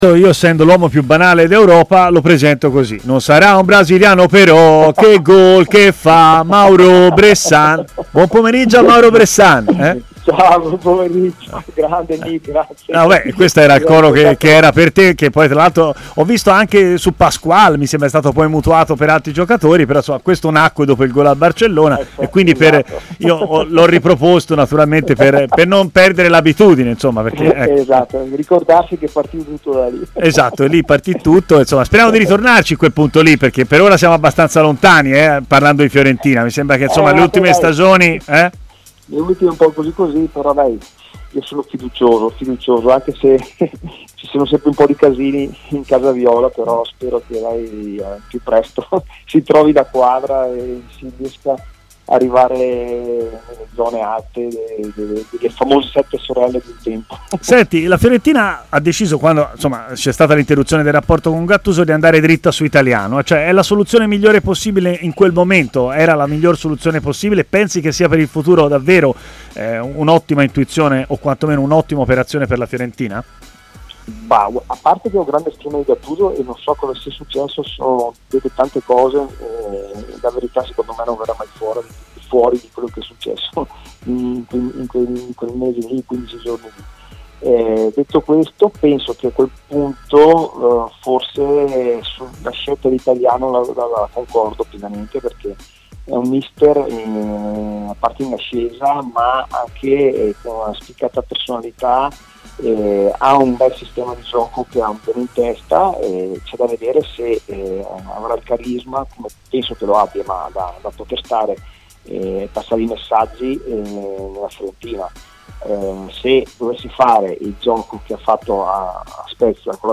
L'ex centrocampista Mauro Bressan ha così parlato in diretta a Stadio Aperto, trasmissione di TMW Radio